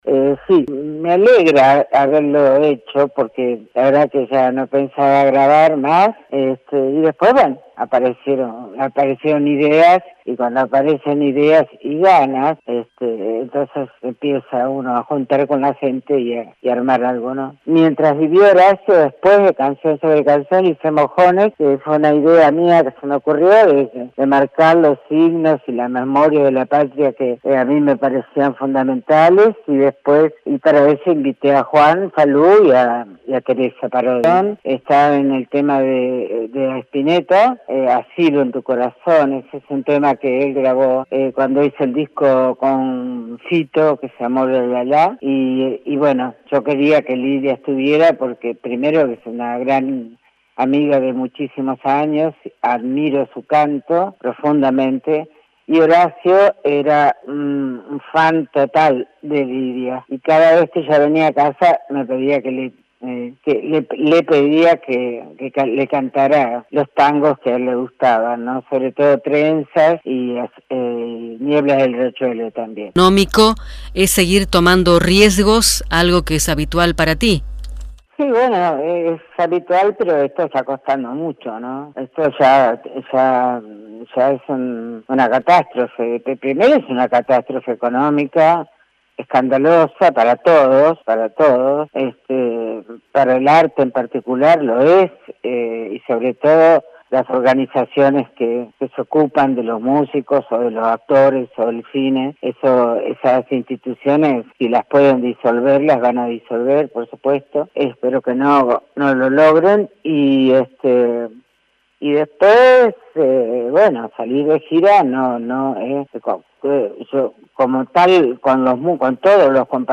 La artista dialogó con Folktango Interior a horas de iniciar su gira cordobesa, presentando su último trabajo Fuera de lugar.